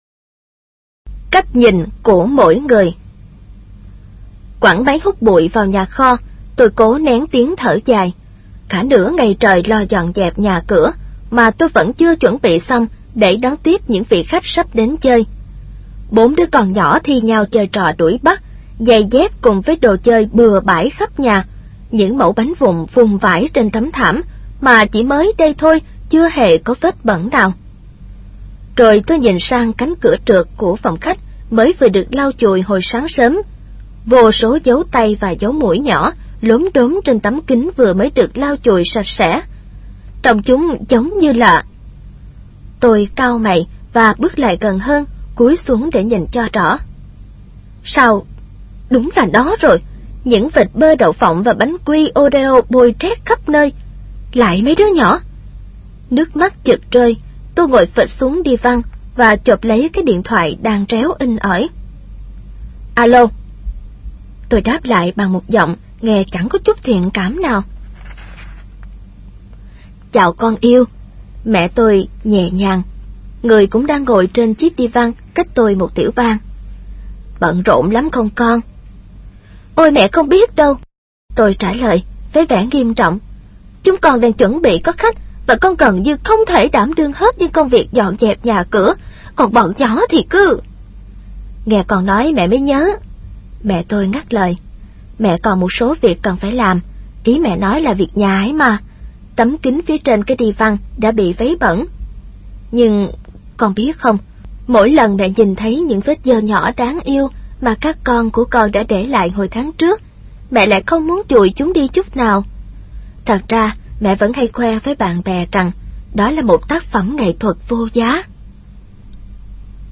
Sách nói Chicken Soup 3 - Chia Sẻ Tâm Hồn Và Quà Tặng Cuộc Sống - Jack Canfield - Sách Nói Online Hay